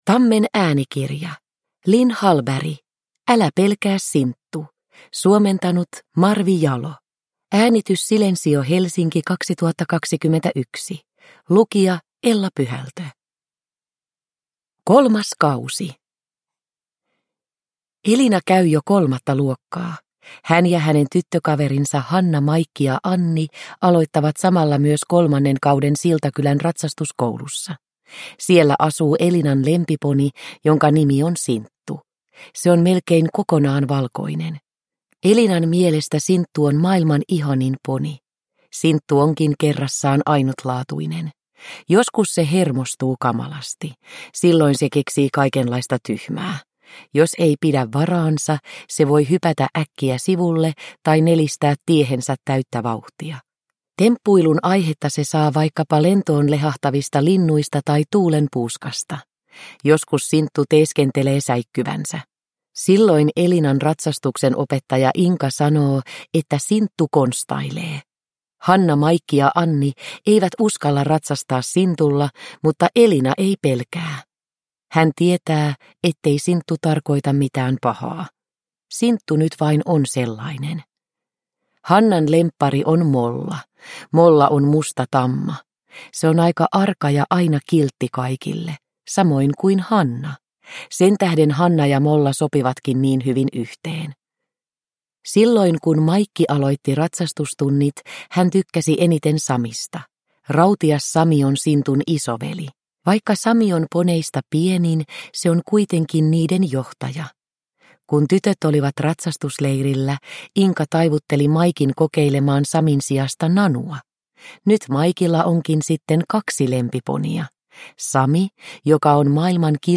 Älä pelkää, Sinttu! – Ljudbok – Laddas ner